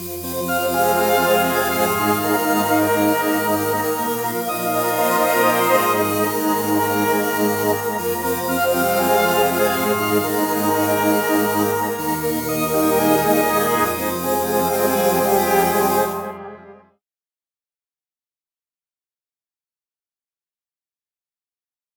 4 layers mix